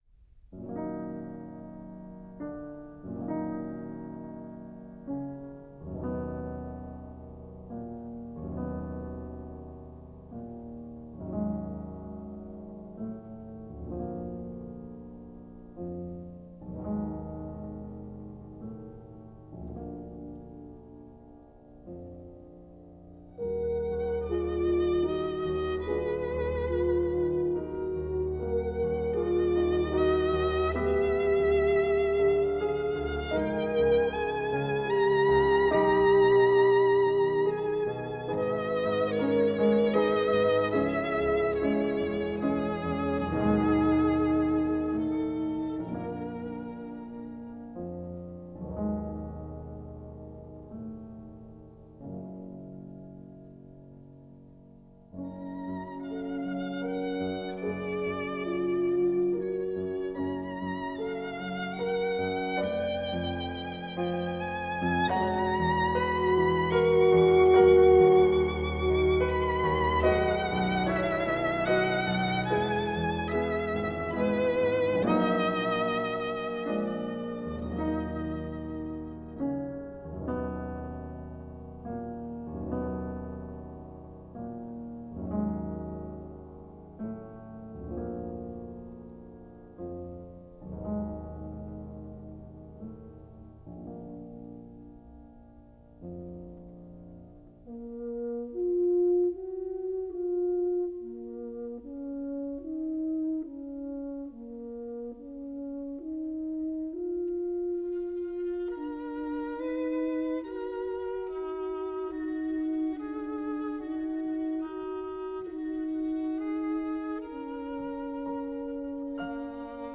Brahms, Trio in E flat, Op. 40 for piano, violin and horn, iii Adagio
1-07-trio-in-e-flat-op-40-for-piano-violin-and-horn-iii-adagio-mesto.m4a